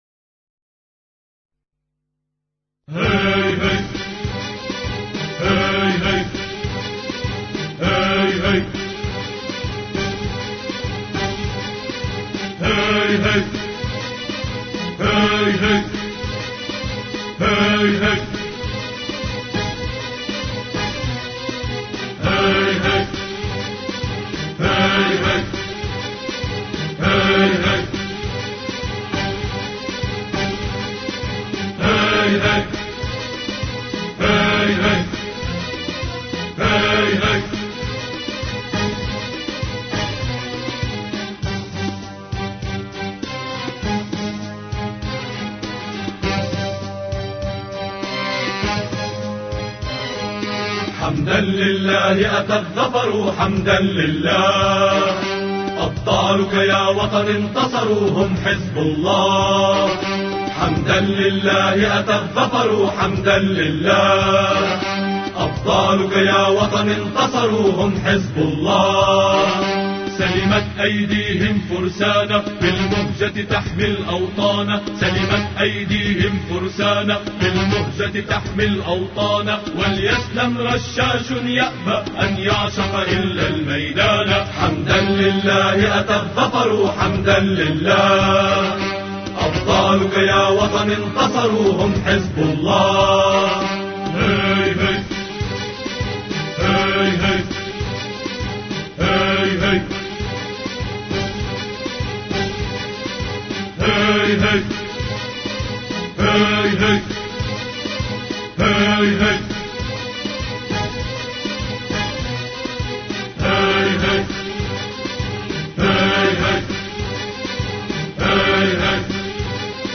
أناشيد لبنانية